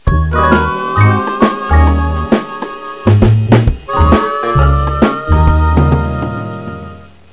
Stings